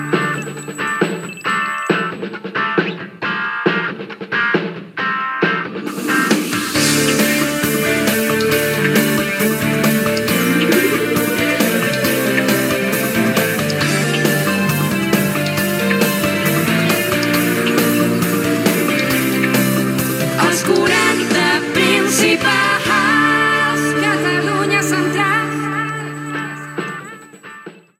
Indicatiu de l 'emissora